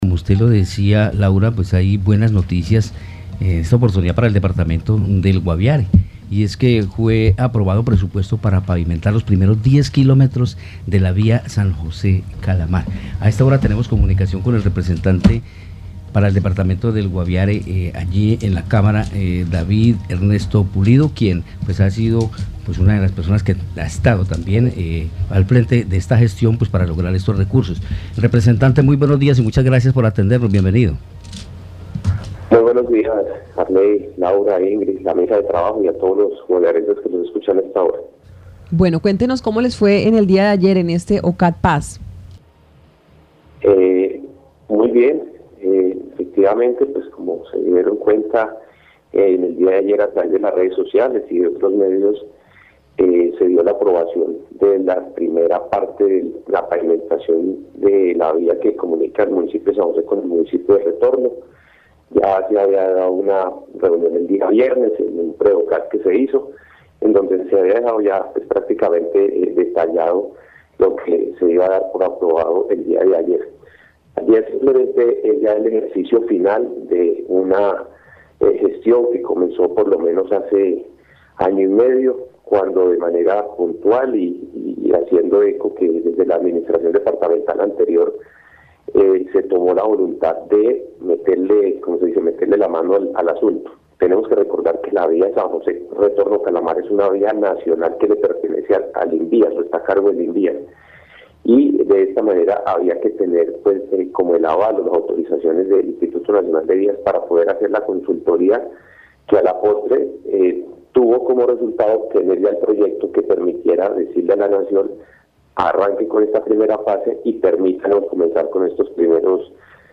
Escuche a David Pulido, representante a la Cámara por el Guaviare.